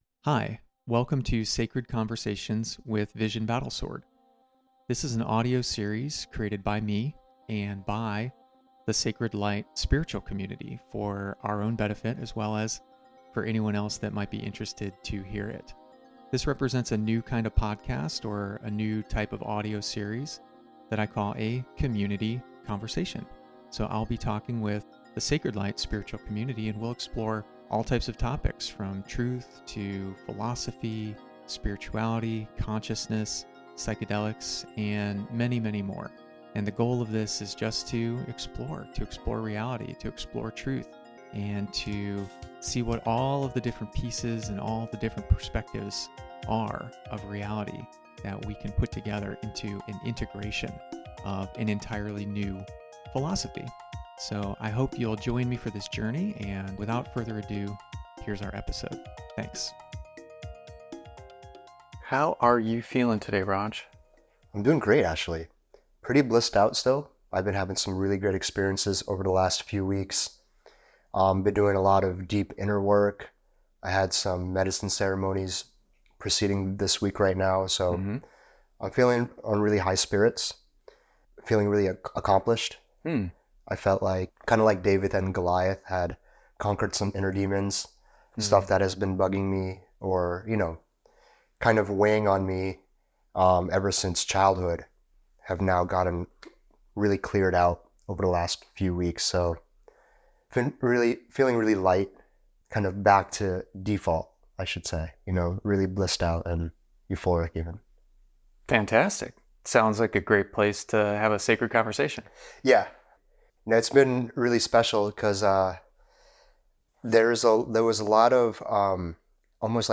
conversation14-abundance.mp3